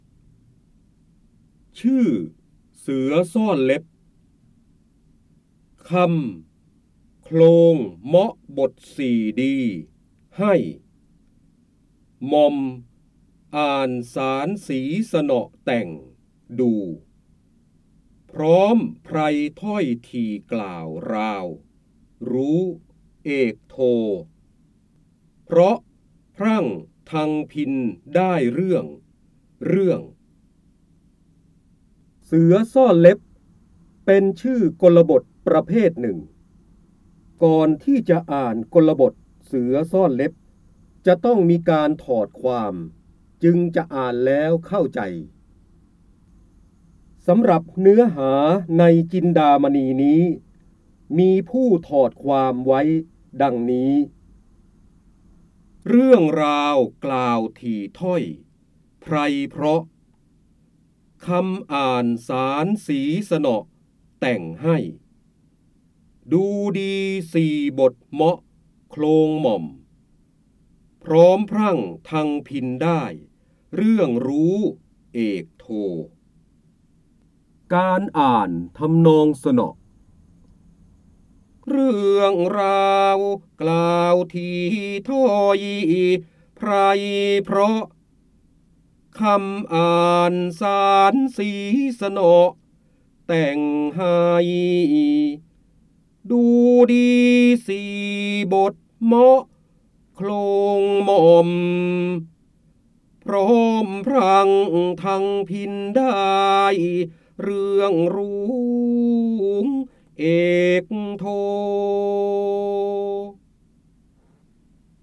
เสียงบรรยายจากหนังสือ จินดามณี (พระโหราธิบดี) ชื่อเสือซ่อนเล็บ
คำสำคัญ : พระเจ้าบรมโกศ, จินดามณี, พระโหราธิบดี, ร้อยกรอง, ร้อยแก้ว, การอ่านออกเสียง
ลักษณะของสื่อ :   คลิปการเรียนรู้, คลิปเสียง